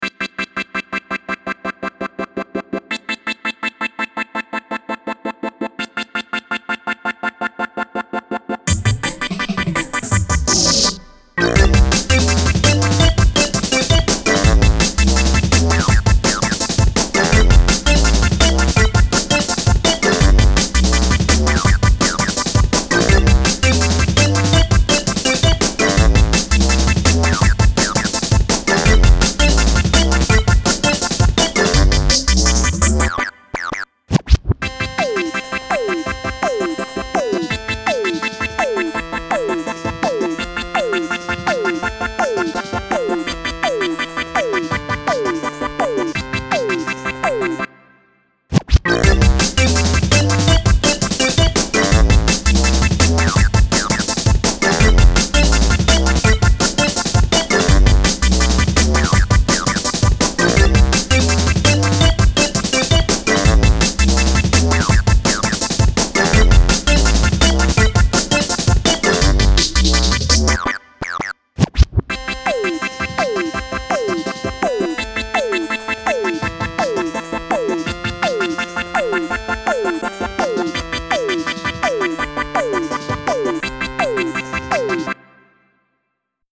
Playful uptempo 8-bit kids computer game drum'n'bass tune.